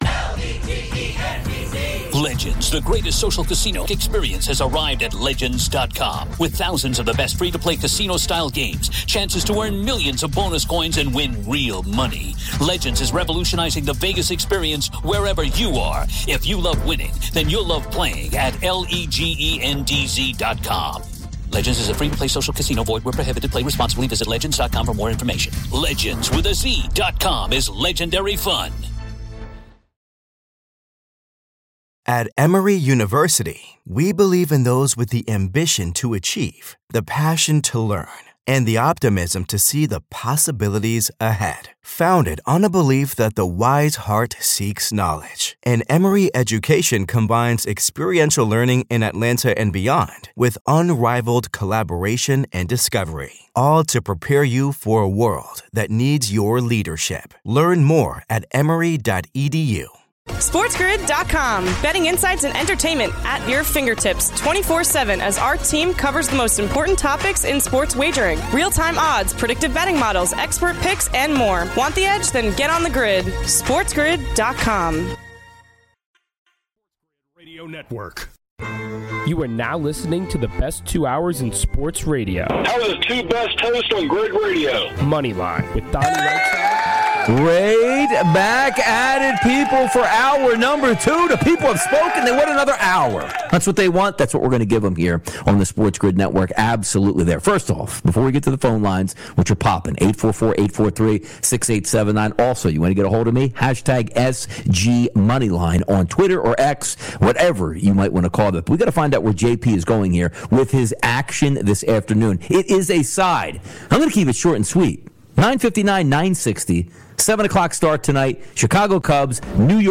All this, your calls, his plays, and more!